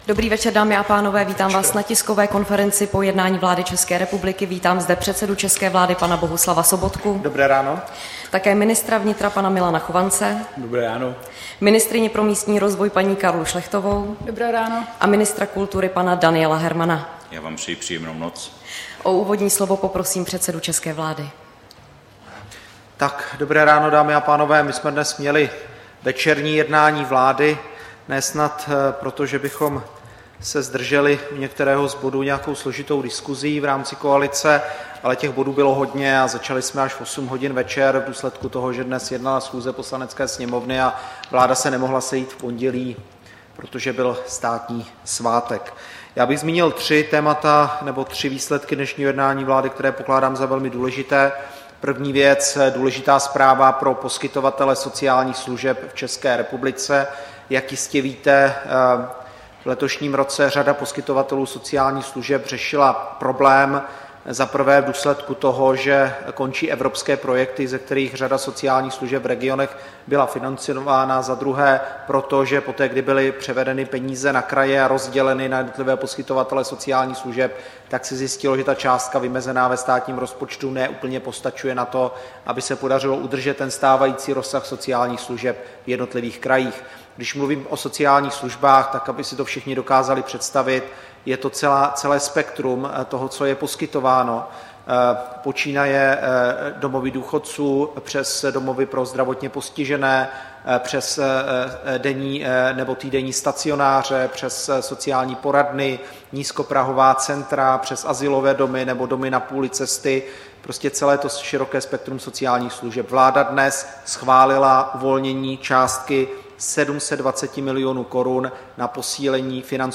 Tisková konference po jednání vlády 8. července 2015